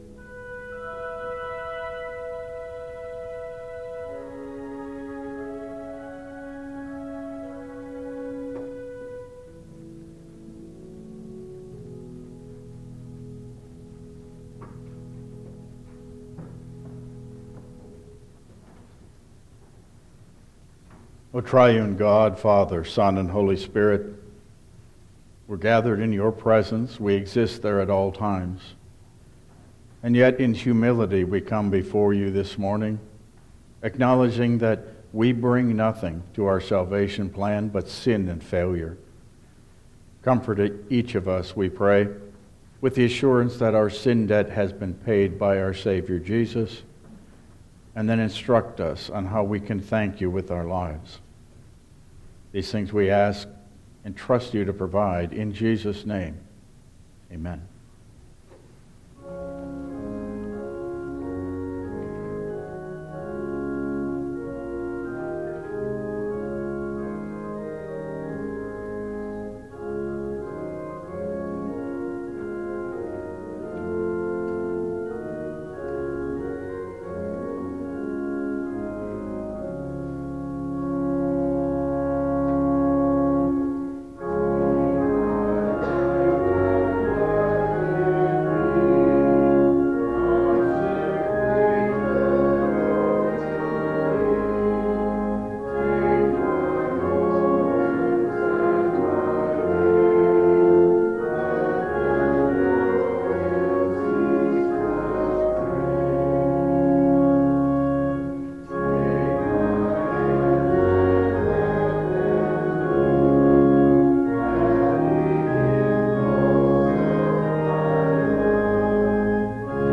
1 Corinthians 1:26-31 Service Type: Regular Service Download Files Printed Sermon and Bulletin Topics: Trust and Humility in All Circumstances , Why Me?